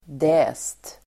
Ladda ner uttalet
Uttal: [dä:st]